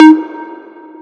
Bell [4].wav